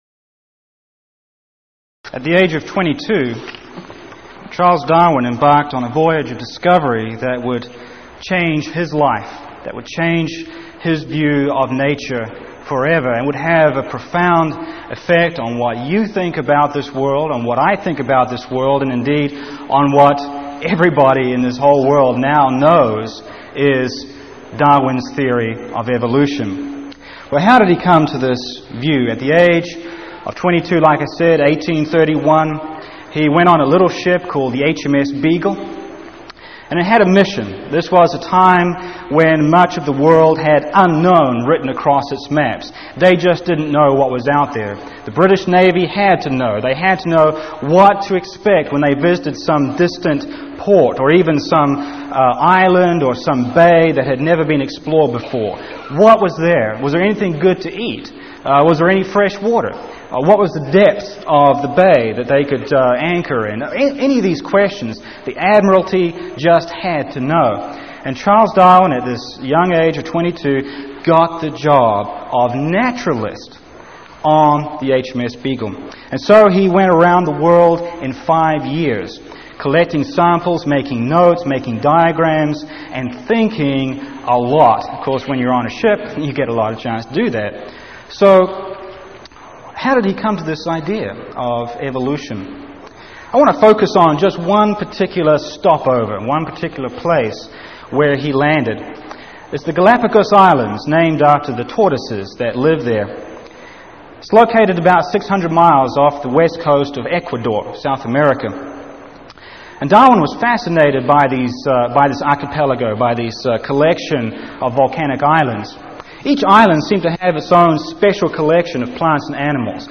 Series: Power Lectures